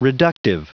Prononciation du mot reductive en anglais (fichier audio)
Prononciation du mot : reductive